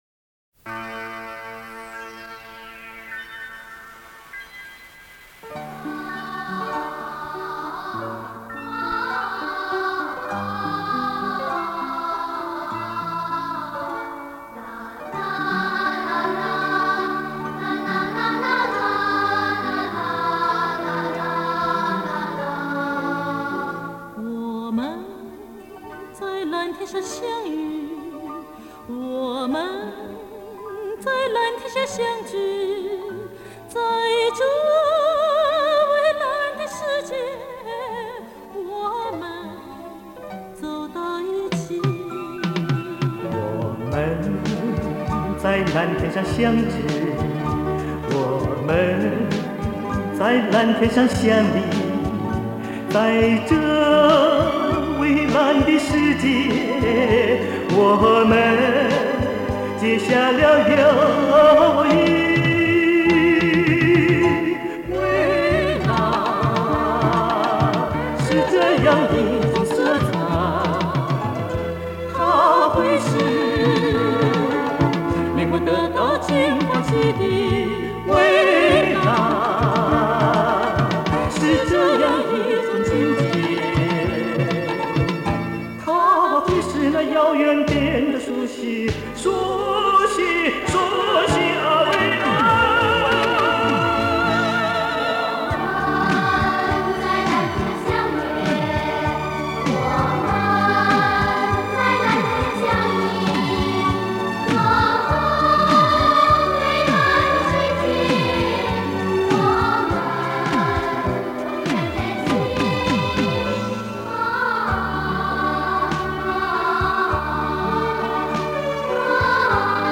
盒带音轨。